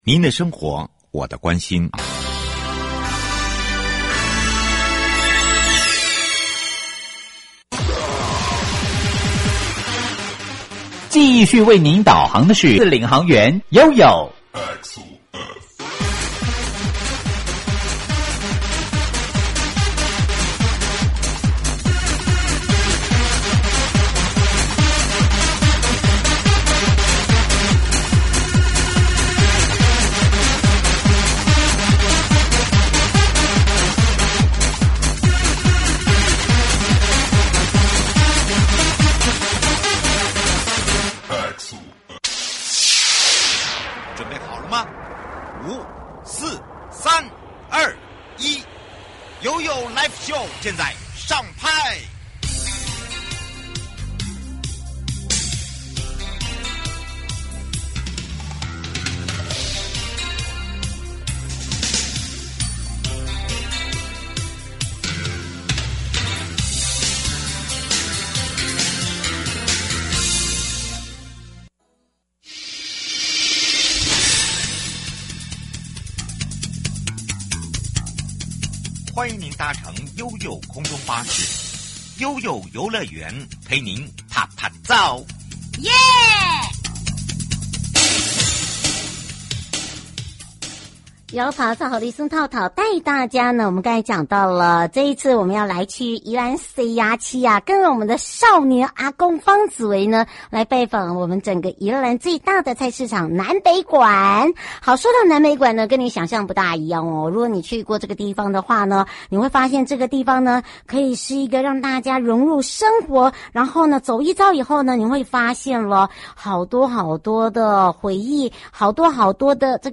受訪者： 東北角管理處 東北角觀光圈